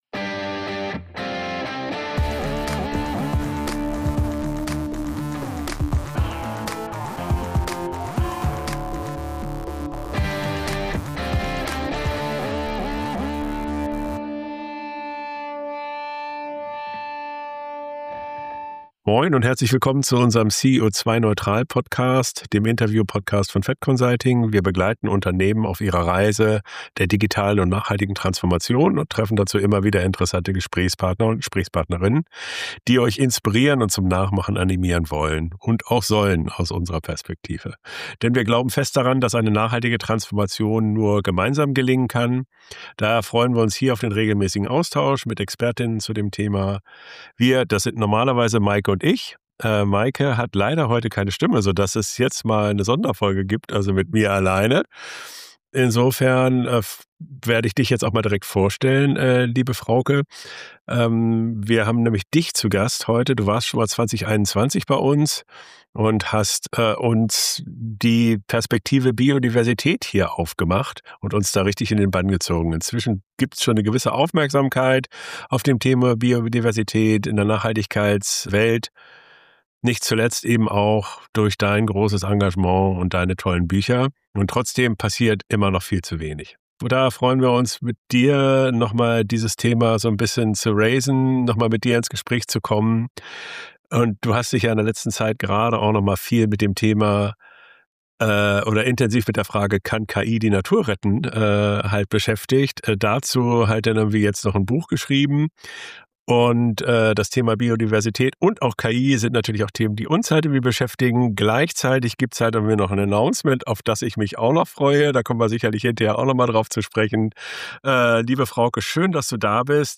CEO2-neutral - Der Interview-Podcast für mehr Nachhaltigkeit im Unternehmen Podcast